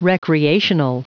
Prononciation du mot recreational en anglais (fichier audio)
Prononciation du mot : recreational